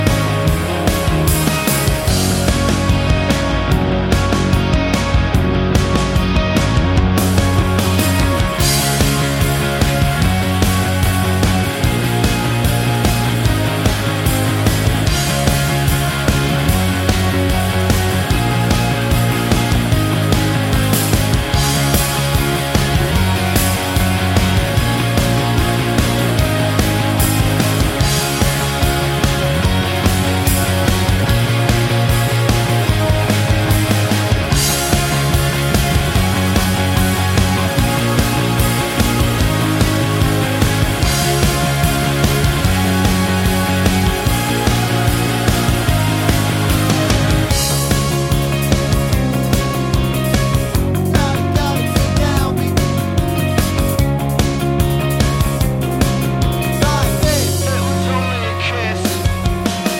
remstr Pop (2010s) 3:40 Buy £1.50